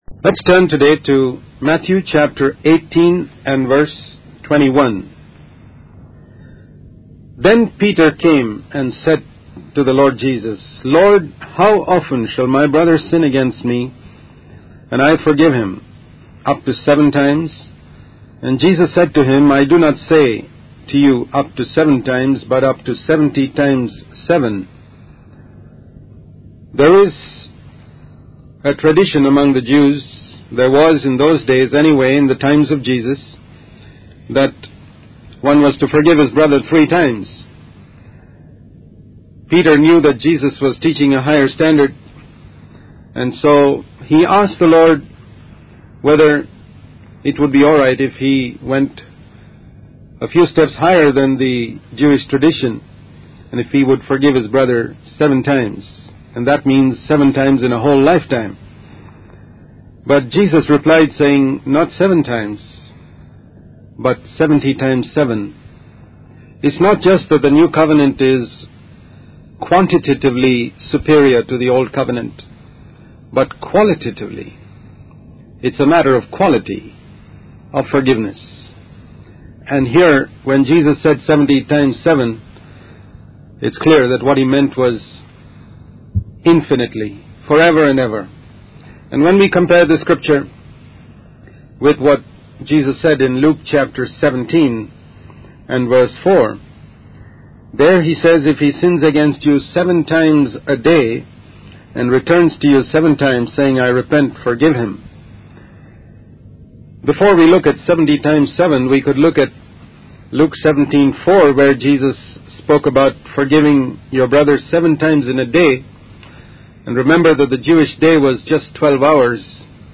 In this sermon, Jesus addresses the attitude of seeking rewards for serving God. He emphasizes that one cannot serve both God and money, and that the love of money can hinder one from obtaining eternal life.